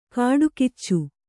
♪ kāḍu kiccu